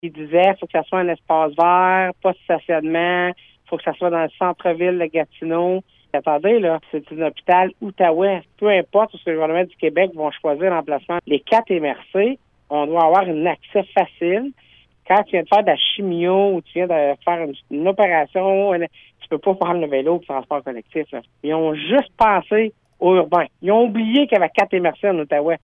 Cependant, pour les préfets de l’Outaouais, l’enjeu du transport actif est loin d’être primordial dans ce dossier, comme l’indique la préfète de la Vallée-de-la-Gatineau, Chantal Lamarche :